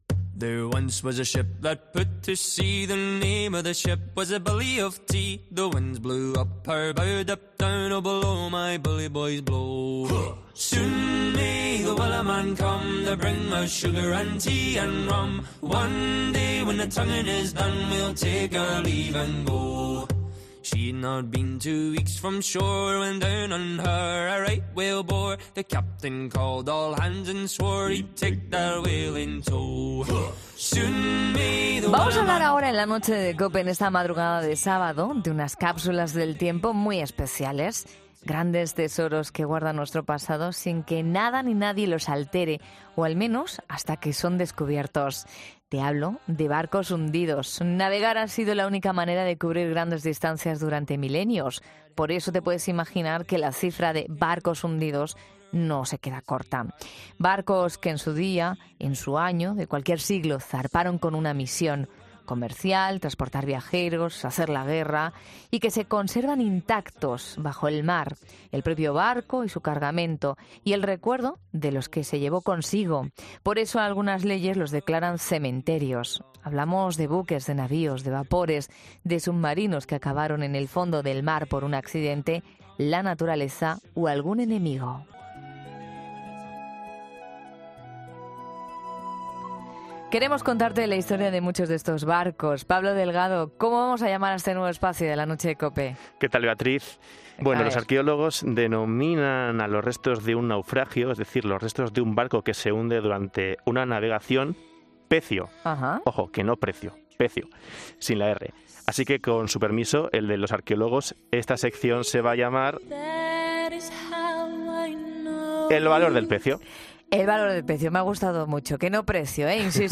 Redacción digital Madrid - Publicado el 16 sep 2022, 17:24 - Actualizado 26 jun 2023, 11:04 2 min lectura Facebook Twitter Whatsapp Telegram Enviar por email Copiar enlace Primera entrega de ''El valor del pecio'', un espacio de radio de la Cadena COPE dedicado al patrimonio subacuático.